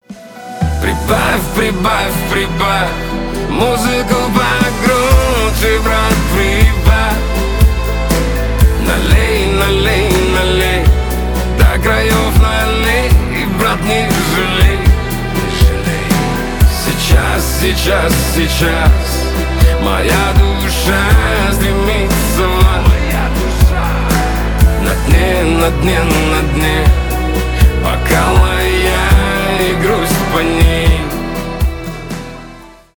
шансон , душевные
грустные